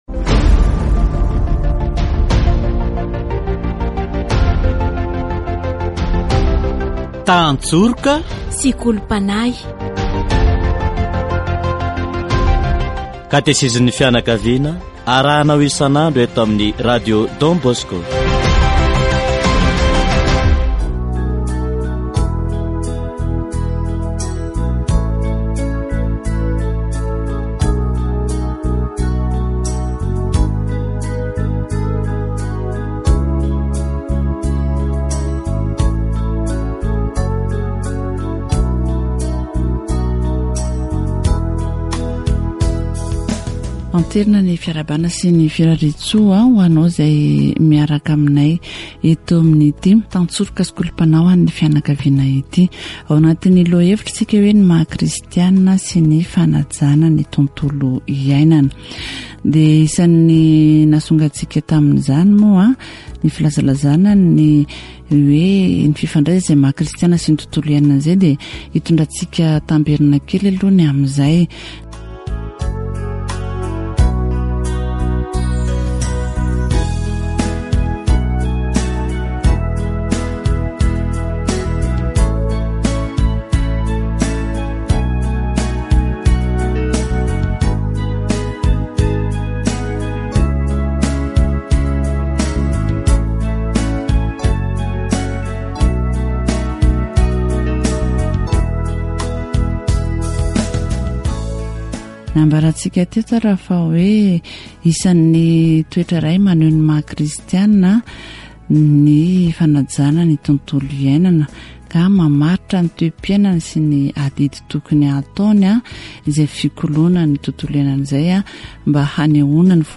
Catechesis on Christian Commitments and the Environment